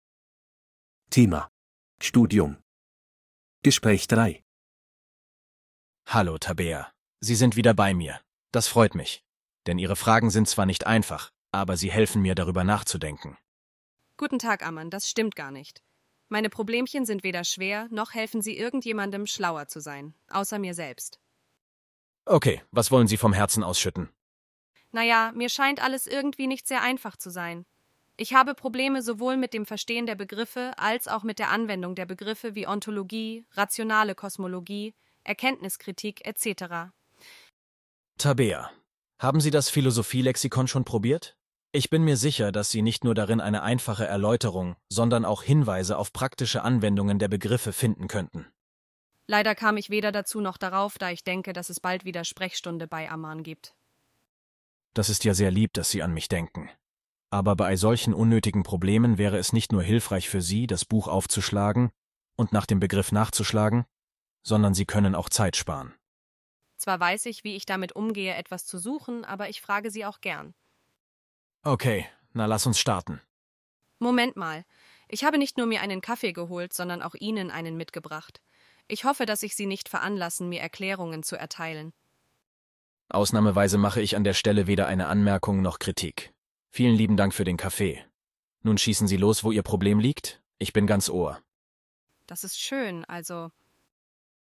Bài nghe hội thoại 3:
B1-Registeruebung-5-Studium-Gespraech-3.mp3